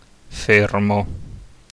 Fermo (Italian: [ˈfermo]